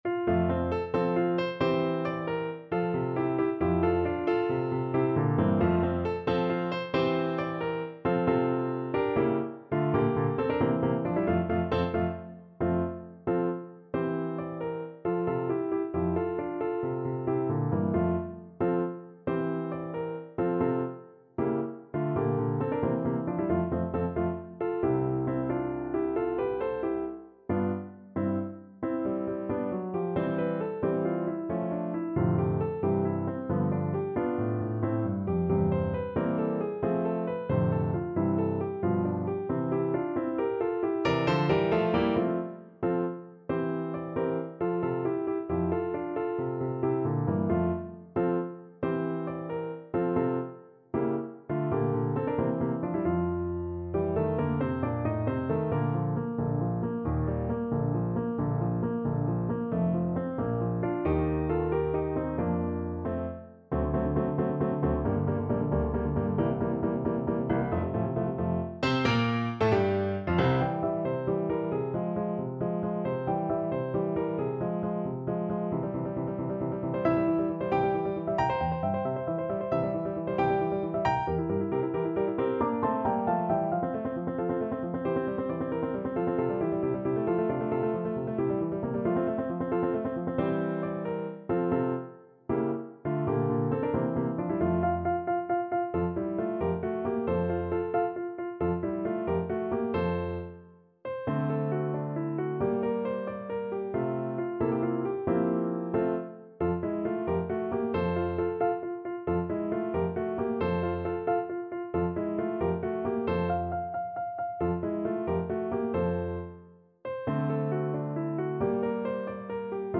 6/8 (View more 6/8 Music)
. = 90 Allegretto vivace
Classical (View more Classical Flute Music)